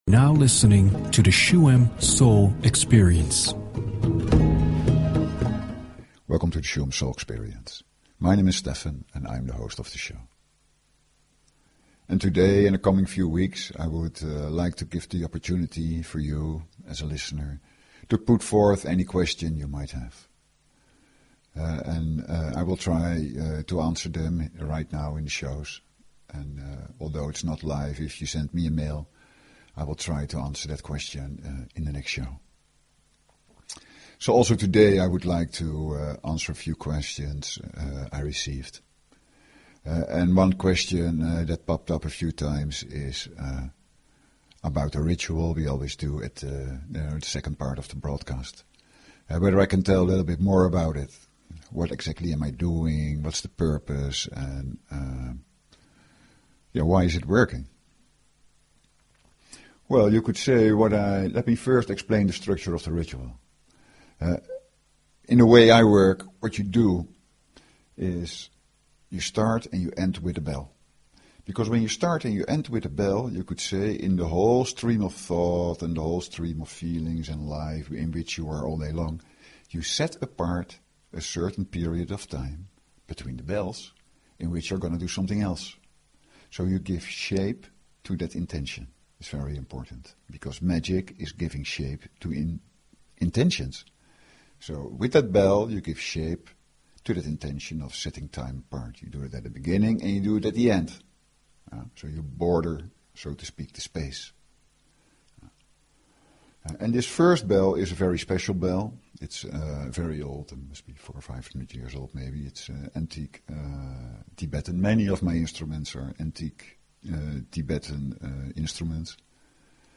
Talk Show Episode, Audio Podcast, Shuem_Soul_Experience and Courtesy of BBS Radio on , show guests , about , categorized as
Each instrument has a different resonance and provokes a different feeling.
A ritual and instruments that stem from ancient traditions. The second part of the show is a Shuem Meditation Ritual.